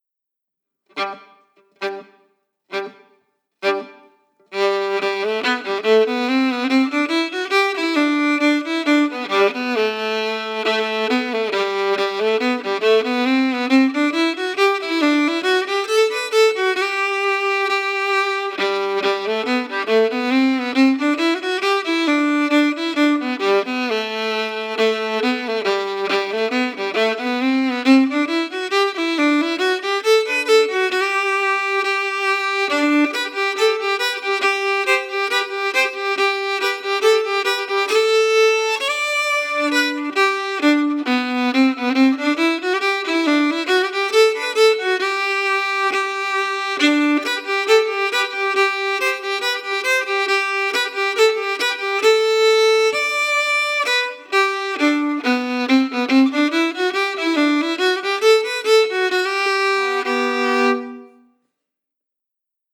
Key: G
Form: Reel
Played slowly for learning
Region: Appalachia